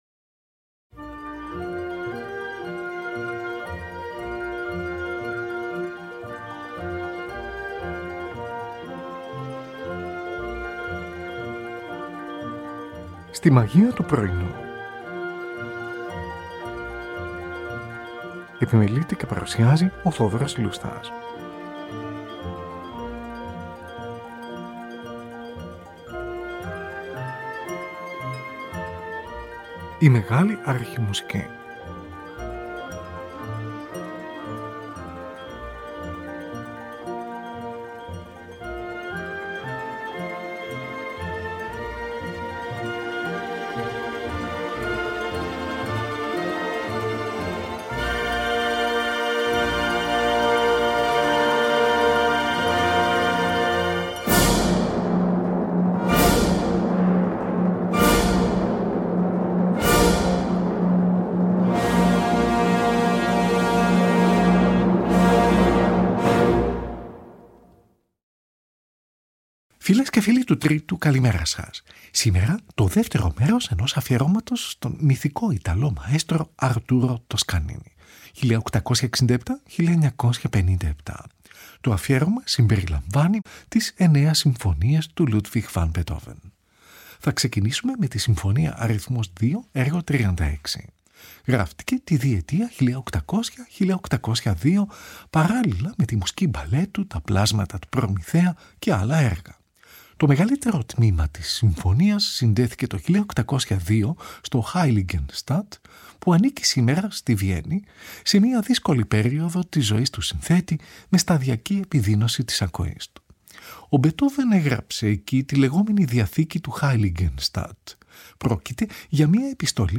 Τη Συμφωνική του NBC διευθύνει ο Arturo Toscanini. Zωντανή ραδιοφωνική μετάδοση , στις 4 Νοεμβρίου 1939.
Giacomo Puccini: Ορχηστρικό intermezzo (πρελούδιο) , της 3ης πράξης, από την όπερα “Manon Lescaut”. Tην Ορχήστρα της Σκάλας του Μιλάνου διευθύνει ο Arturo Toscanini. Zωντανή ηχογράφηση , στις 11 Μαΐου 1946.